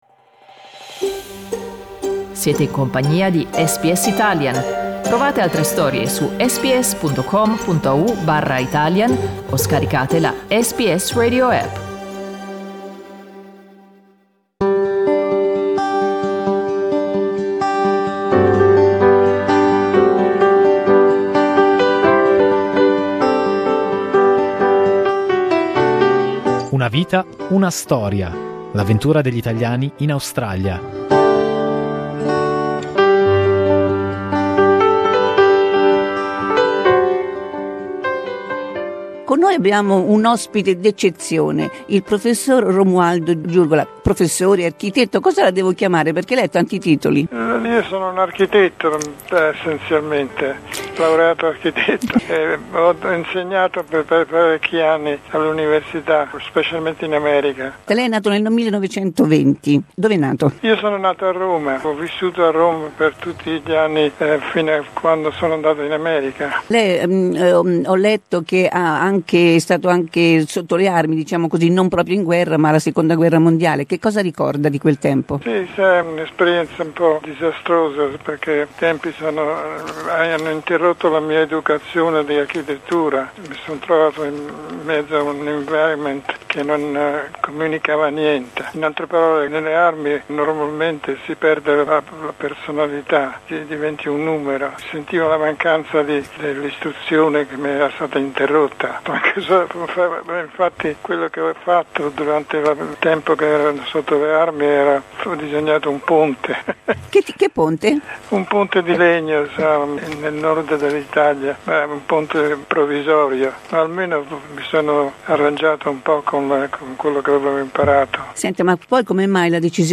SBS Italian aveva intervistato Romaldo Gurgiola nel 2013 e oggi vi riproponiamo quell'intervista.